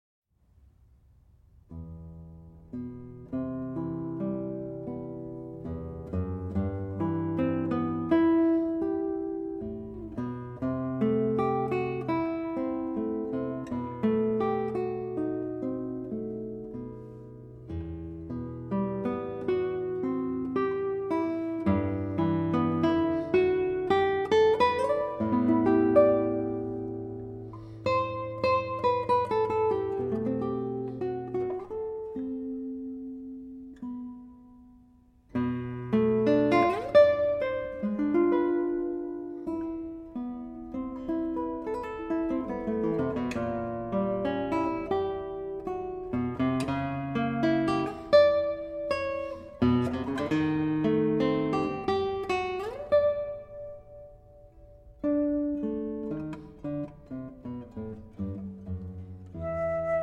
Guitar
Flute